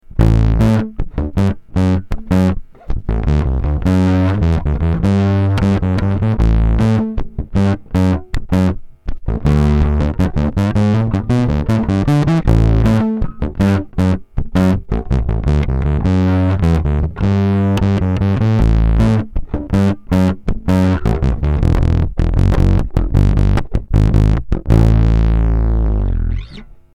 gated bass fuzz